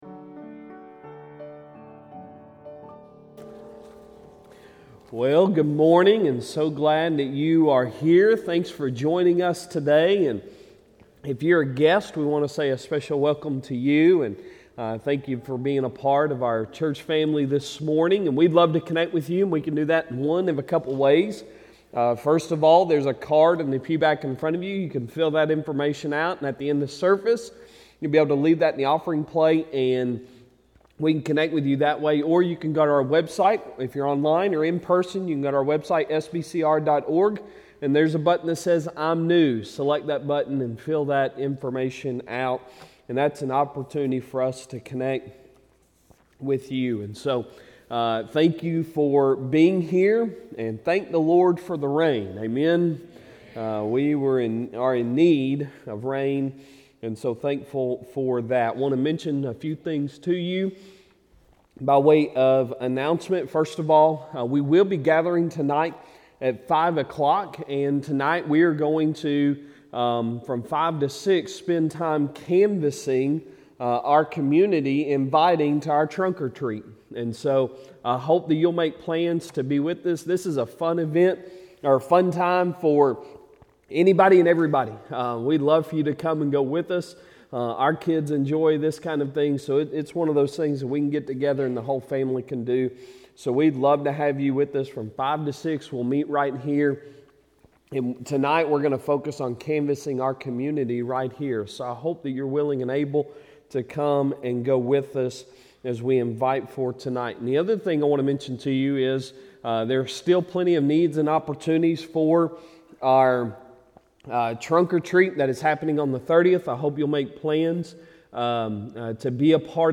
Sunday Sermon October 16, 2022